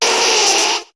Cri de Nidoqueen dans Pokémon HOME.